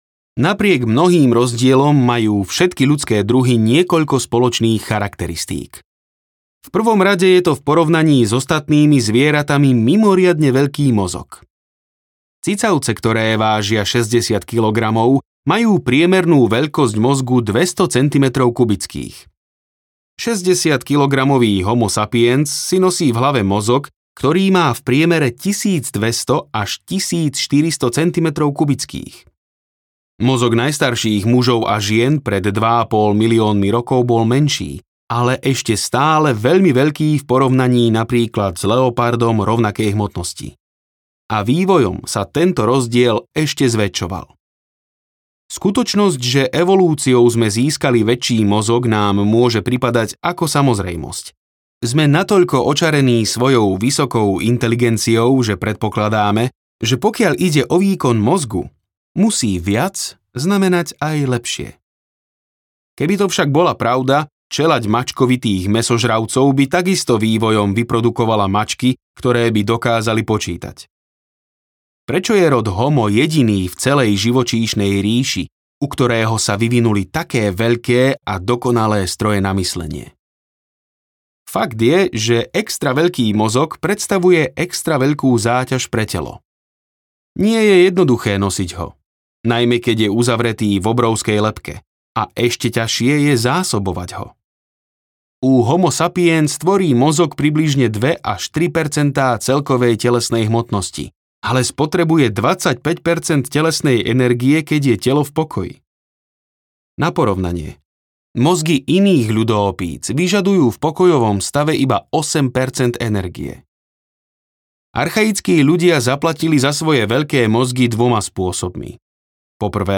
Audiokniha Sapiens - Yuval Noah Harari | ProgresGuru
Sapiens: Stručné dejiny ľudstva je pútavá audiokniha, ktorá spochybní všetko, čo ste si mysleli, že viete o dejinách ľudstva. Táto audiokniha, nahovorená s pútavým zanietením, zavedie poslucháčov na vzrušujúci prieskum - od našich skromných začiatkov ako bezvýznamných primátov až po naše súčasné postavenie pánov planéty.